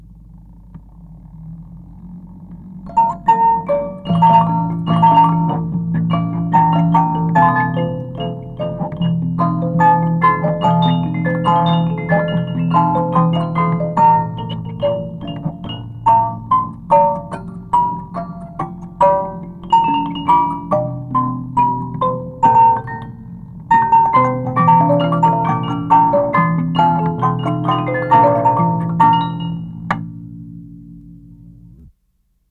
Unknown Ragtime Tunes on Swiss Musical Movement
They are from an uncased, Swiss cylinder musical box mechanism made circa 1905 by Mermod Frères of Ste. Croix, Switzerland.
On hearing the unusual nature of the arrangements, a cassette tape recording was made and that tape has recently resurfaced here at the museum.  From what we are hearing all eight melodies are some of the earliest ragtime arrangements held as a period document via the pinning on the music cylinder.
We apologize for the lack in quality of these cassette tape recordings, which were made back in 1974 while visiting a now deceased collector of antique musical boxes in Geneva, Switzerland.